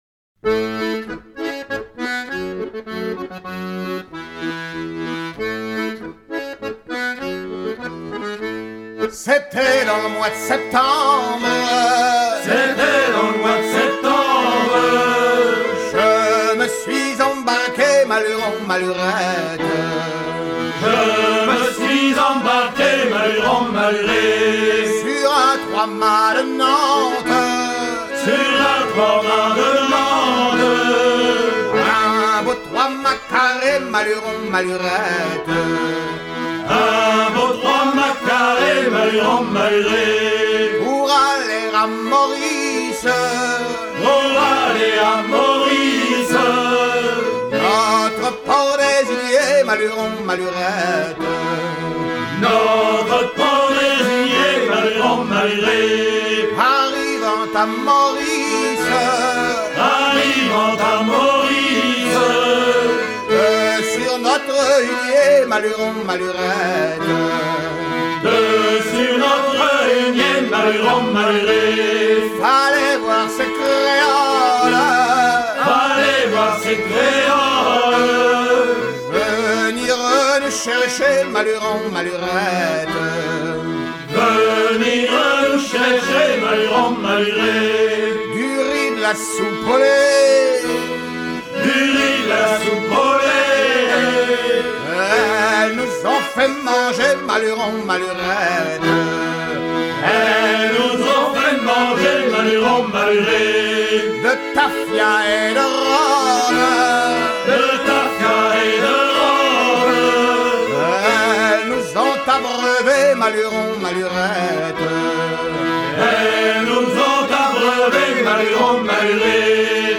à virer au cabestan
circonstance : maritimes
Genre laisse